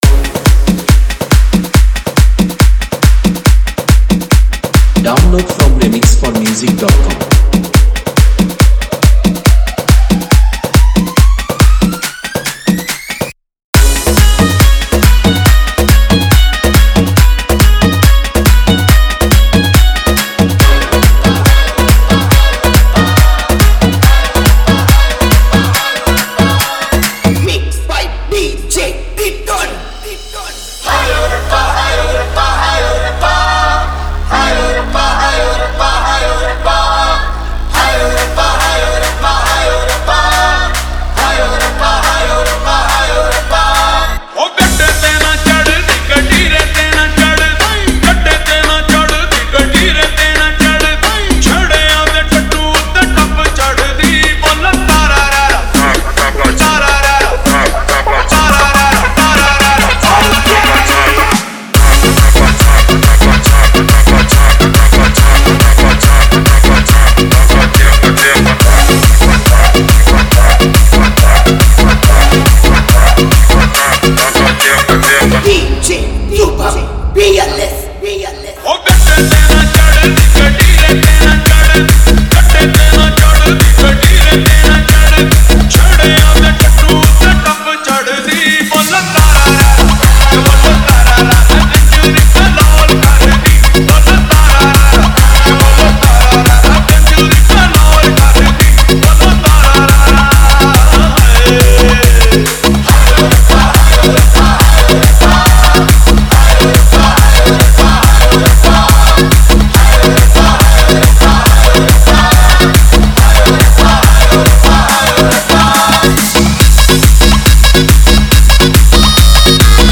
Category : Others Remix Songs